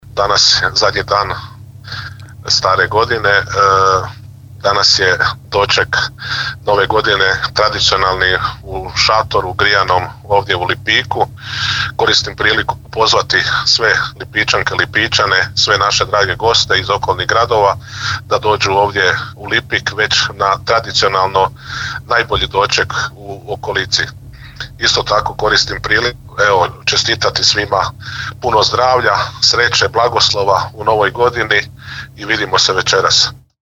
Doček Nove u grijanom šatoru u Lipiku i čestitka gradonačelnika Vinka Kasane
Organizatori i domaćini raduju se vašem dolasku a gradonačelnik Vinko Kasana uz poziv na provod Najluđe noći u Lipiku, želi svima zdravu, sretnu i dobru Novu 2025. godinu: